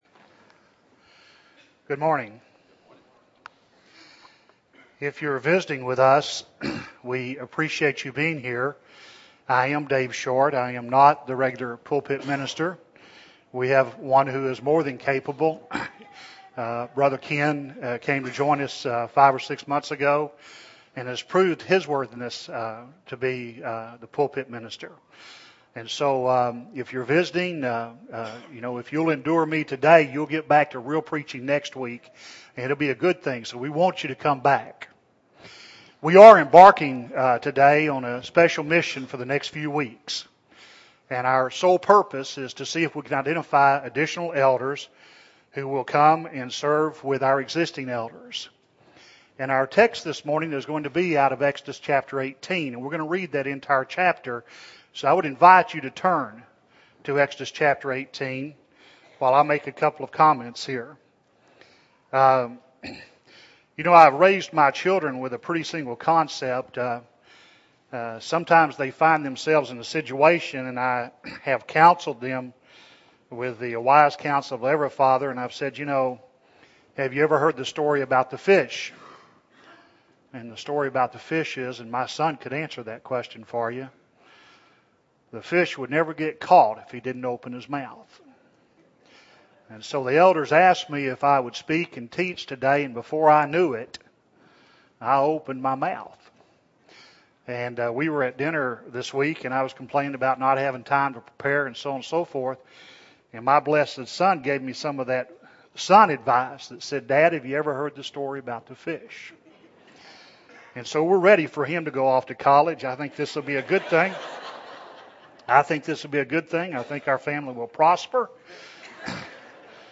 2008-08-10 – Sunday AM Sermon – Bible Lesson Recording